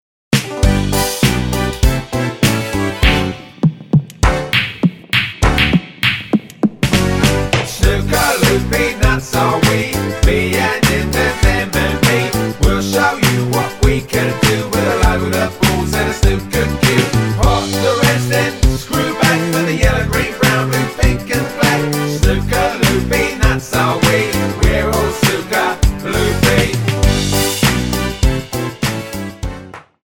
--> MP3 Demo abspielen...
Tonart:G Multifile (kein Sofortdownload.